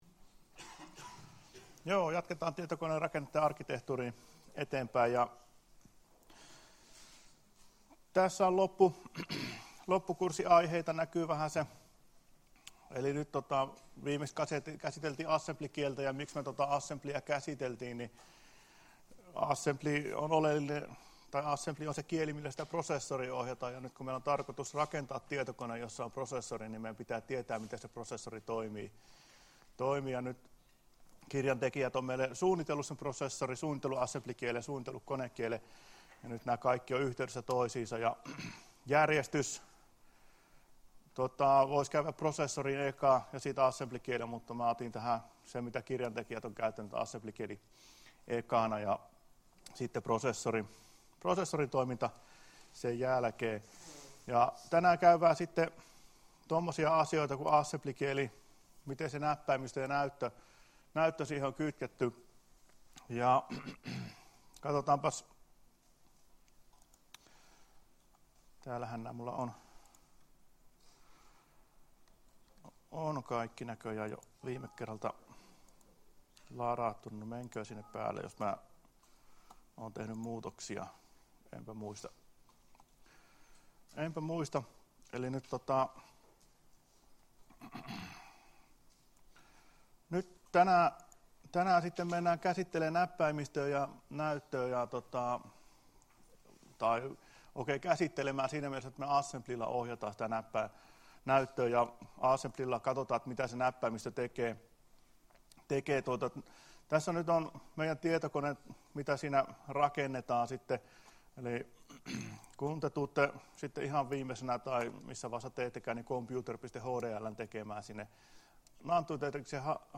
Luento 11.10.2016 — Moniviestin